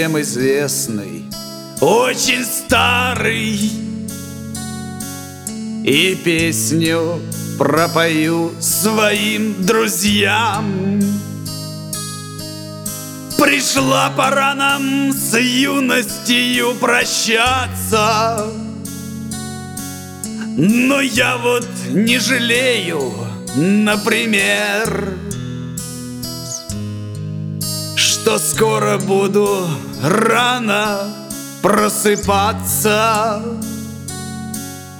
Жанр: Шансон / Русские
# Russian Chanson